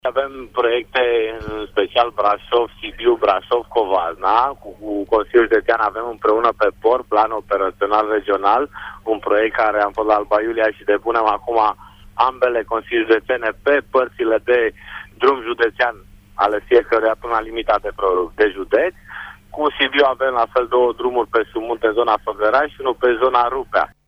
Pentru drumurile care leagă Brașovul de județele limitrrofe, există deja proiecte comune cu vecinii, a mai spus Adrian Gabor: